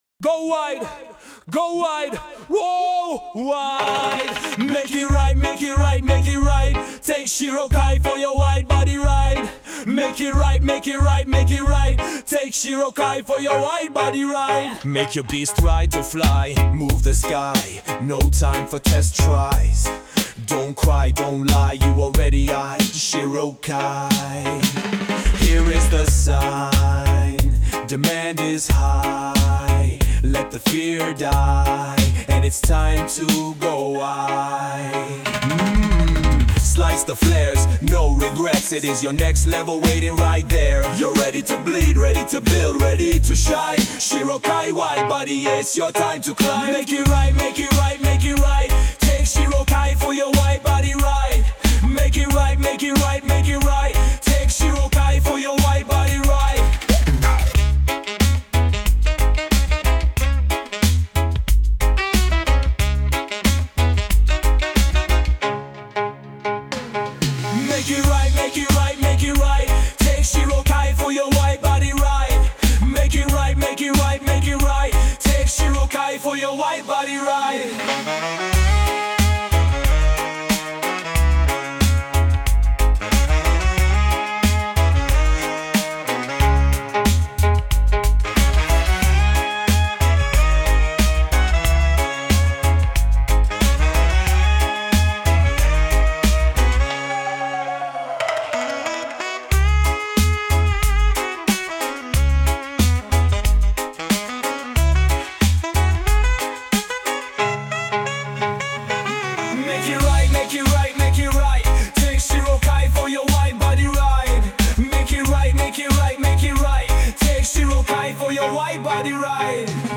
Reggae vibes